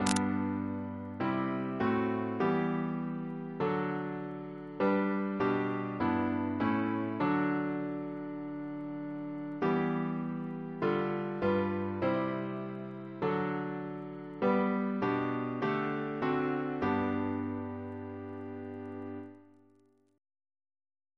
Double chant in G Composer: Sir John Goss (1800-1880), Composer to the Chapel Royal, Organist of St. Paul's Cathedral Reference psalters: ACB: 317; ACP: 94